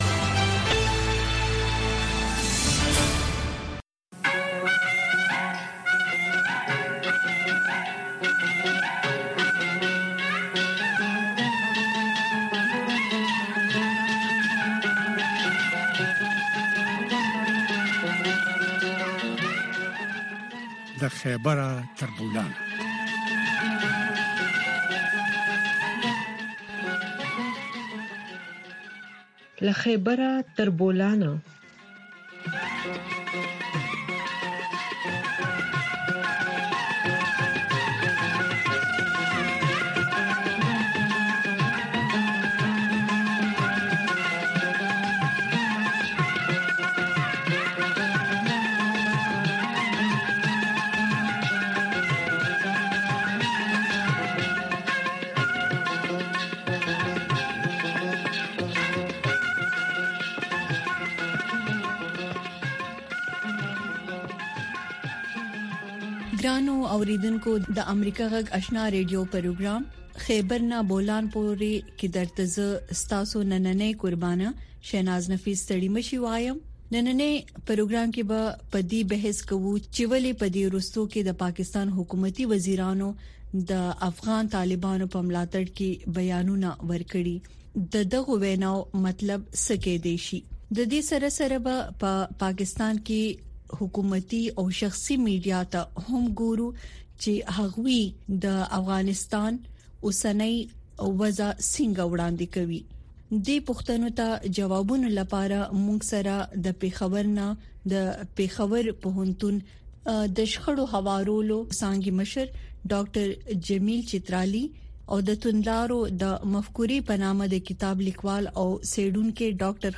په دغې خپرونه کې د روانو چارو پر مهمو مسایلو باندې له اوریدونکو او میلمنو سره خبرې کیږي.